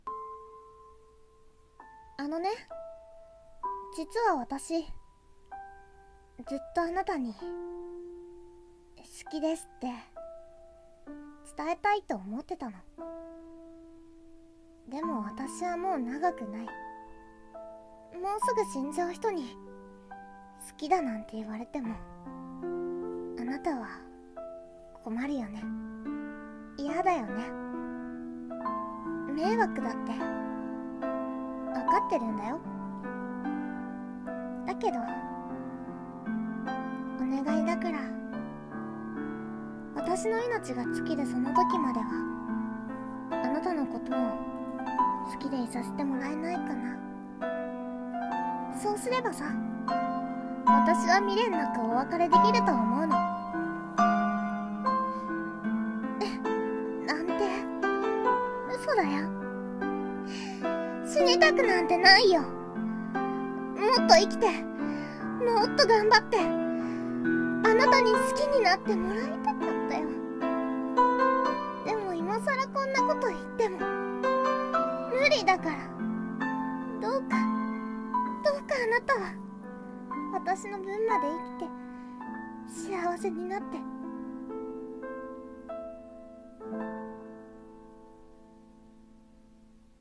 【声劇台本】願い事【切ない系】